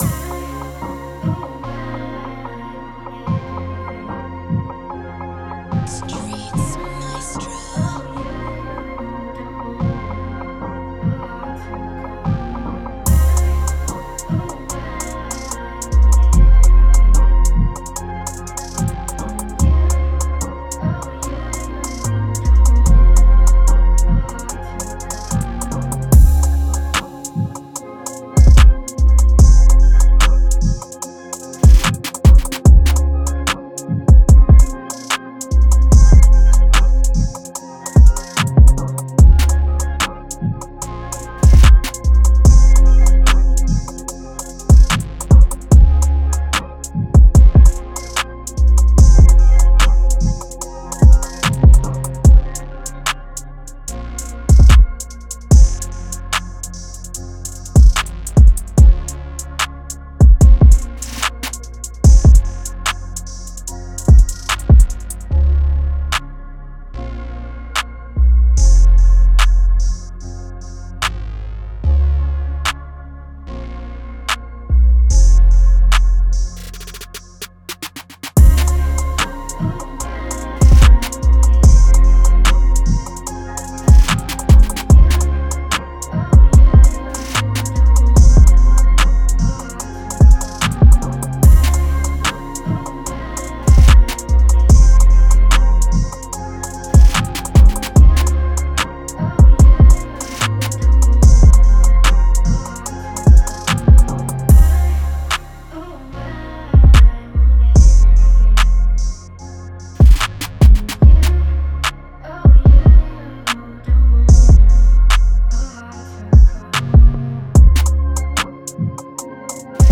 Sexy Drill Type Beat
Moods: smooth, laid back, melodic
Genre: Sexy Drill
Tempo: 147
a smooth, laid back, melodic Sexy Drill type beat.